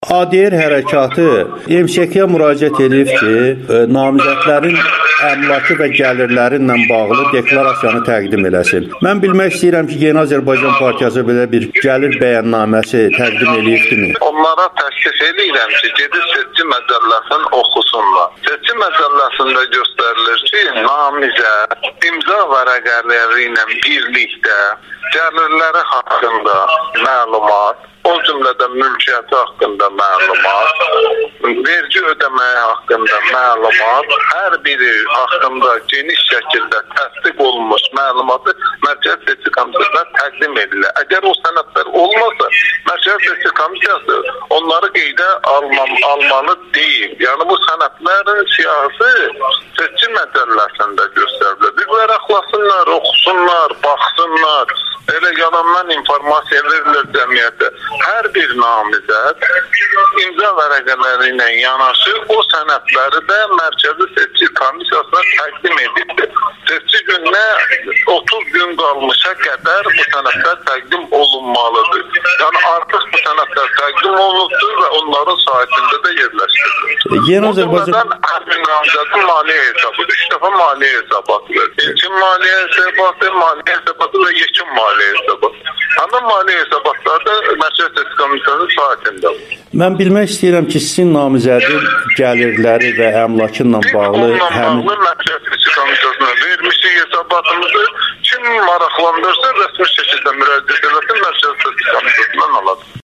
ADR Hərəkatı prezidentliyə namizədlərin gəlirlərinin açıqlanmasını tələb edir (Siyavuş Novruzov və Zahid Orucla müsahibə-audio)